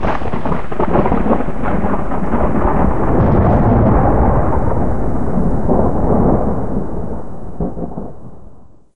Audio-ThunderDistant1.ogg